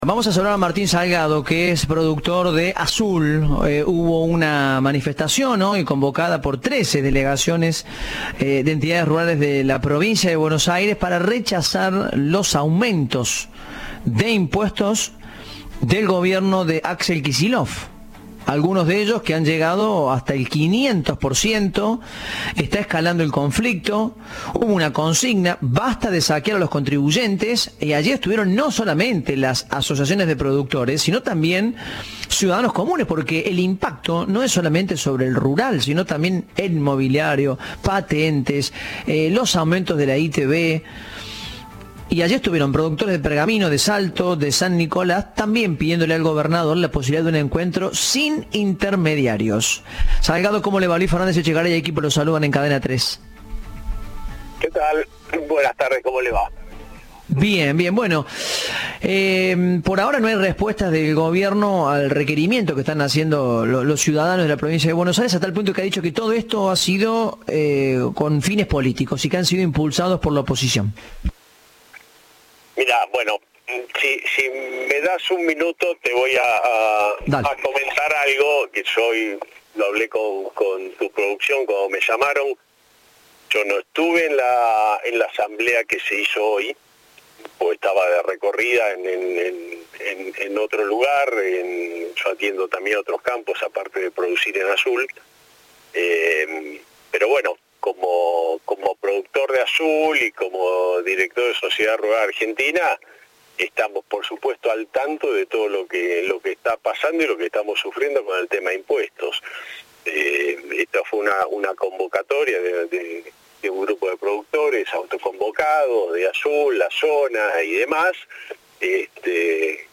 Entrevista de Informados, al regreso